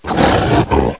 SOUNDS: Add Hellhound Sounds